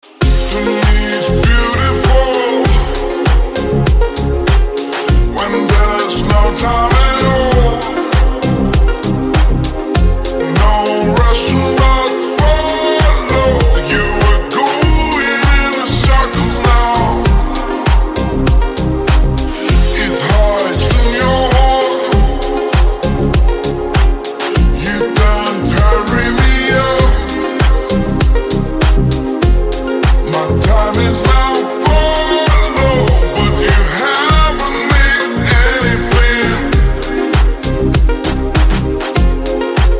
deep house
медленные
ремиксы